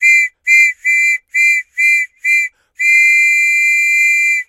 Звуки полицейского свистка